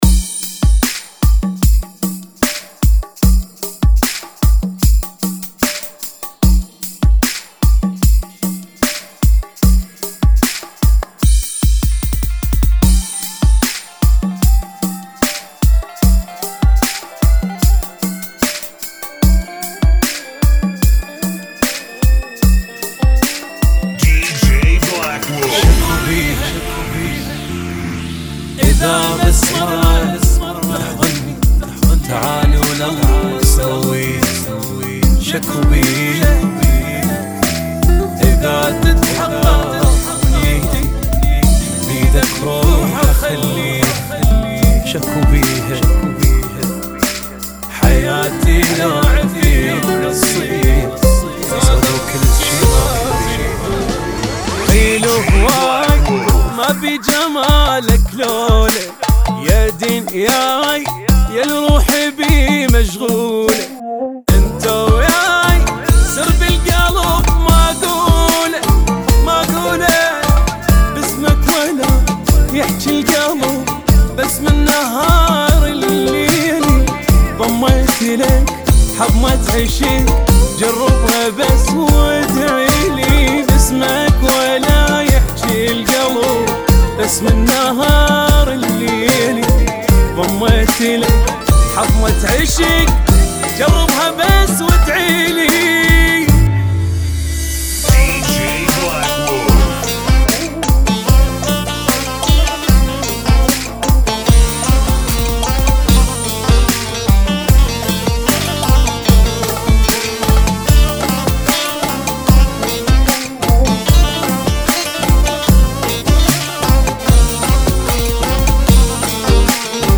75 Bpm